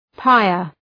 Προφορά
{‘paıər}